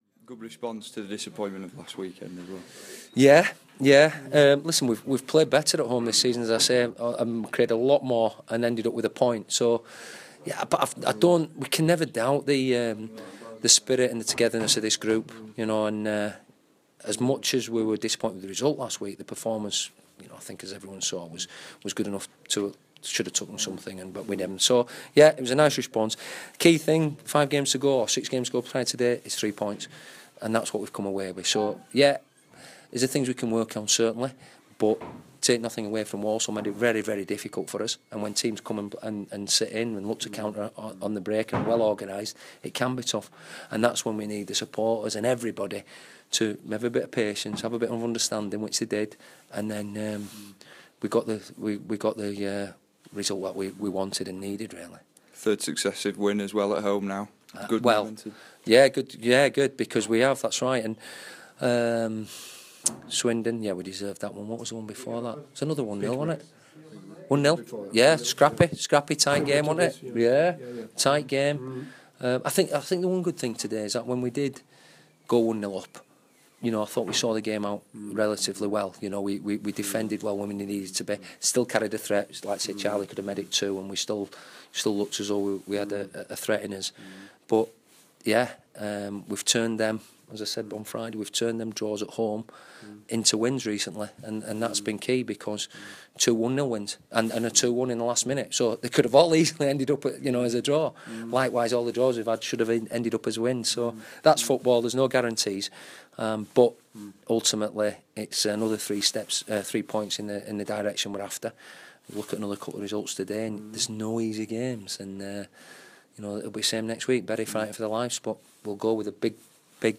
Bantams boss Stuart McCall speaks to Radio Yorkshire after his side beat Walsall 1-0.